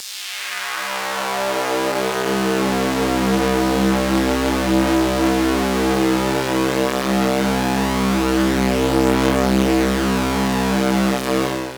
Hp Bass.wav